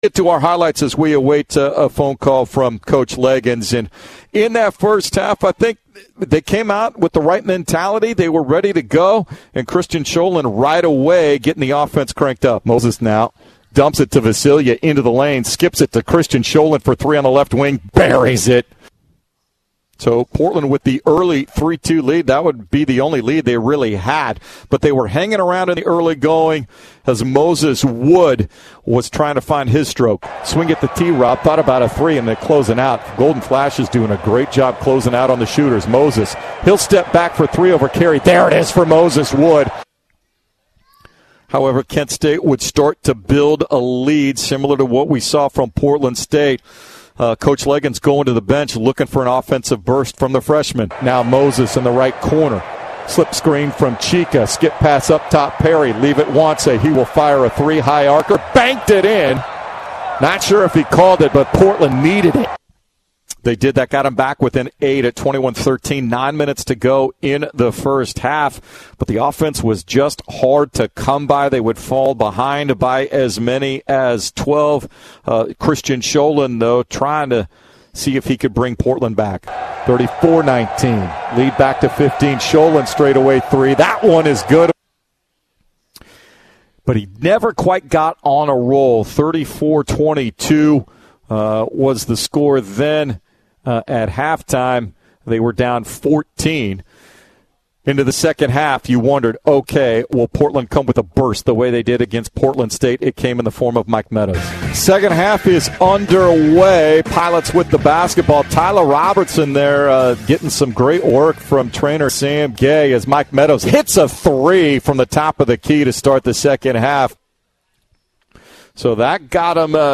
Radio Highlights vs. Kent State
November 14, 2022 Radio highlights from Portland's road loss at Kent State on Nov. 14, 2022.